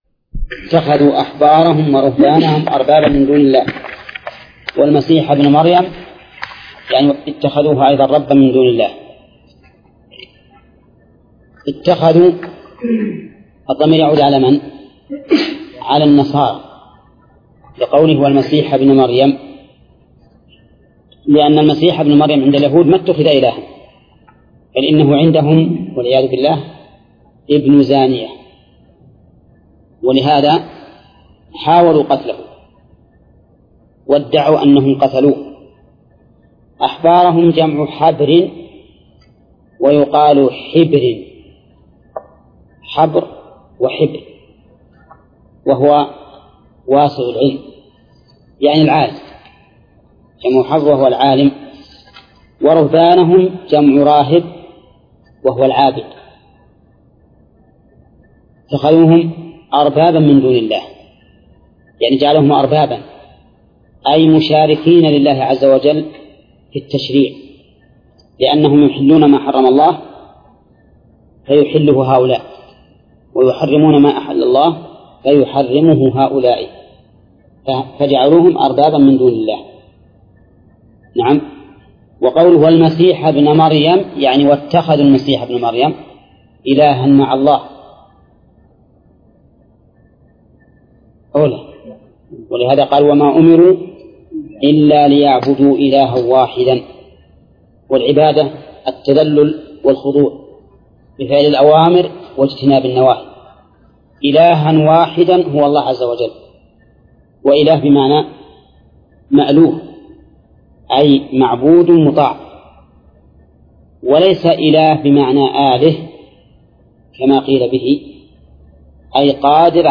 درس (36) / المجلد الثاني : من صفحة: (149)، قوله: (باب من أطاع العلماء والأمراء ..).، إلى صفحة: (166)، قوله: (باب قوله تعالى: {ألم تر إلى الذين يزعمون ..}).